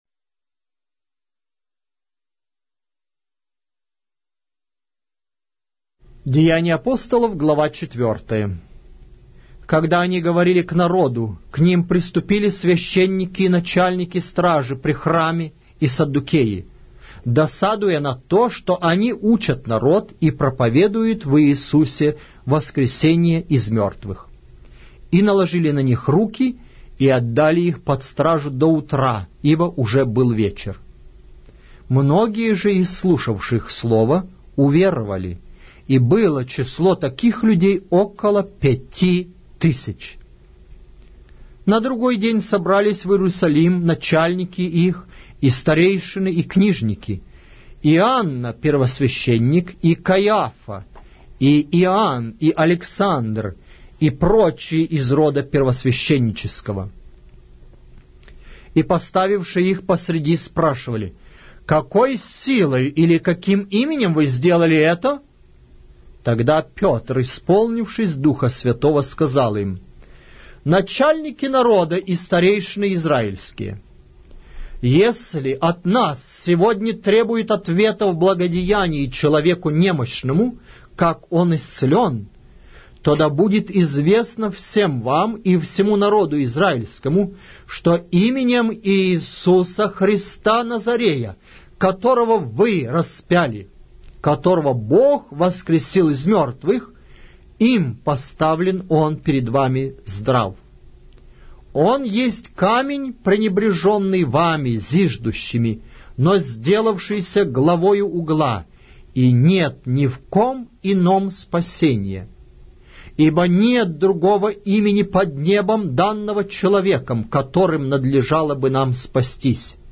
Аудиокнига: Деяния Апостолов